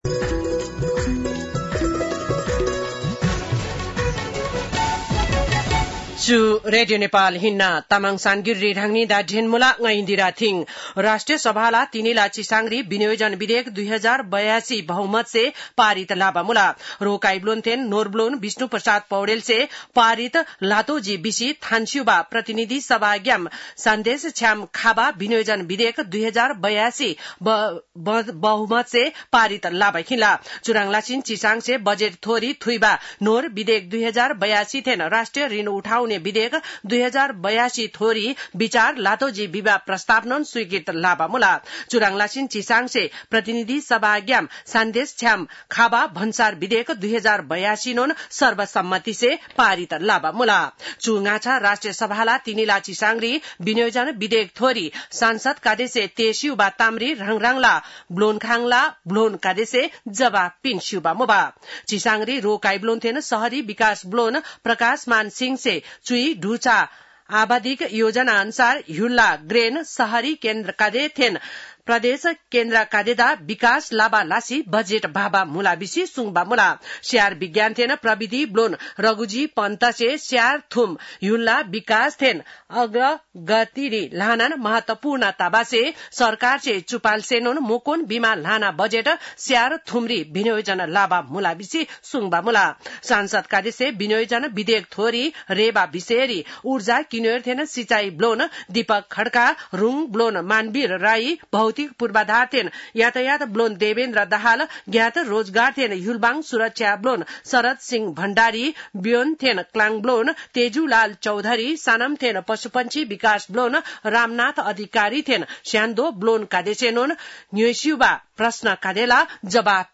तामाङ भाषाको समाचार : १८ असार , २०८२